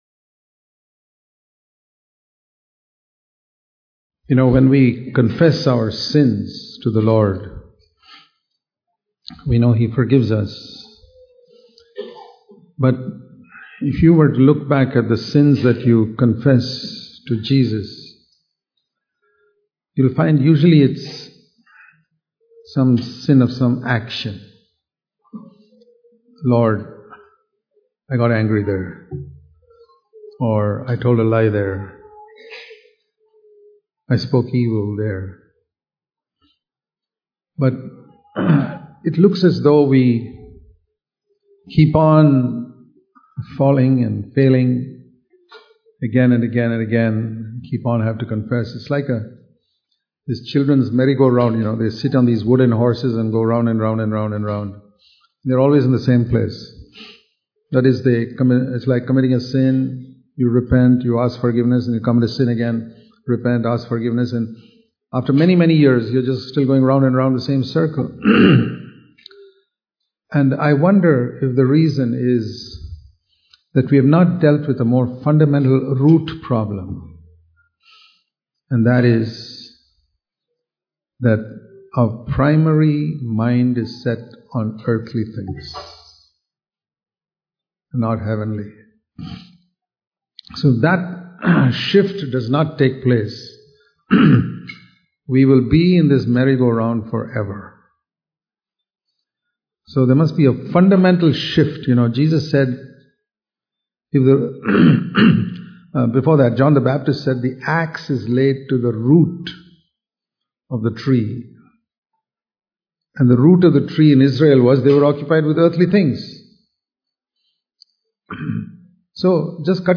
Righteousness, Peace and Joy In the Holy Spirit Dubai Meetings February 2017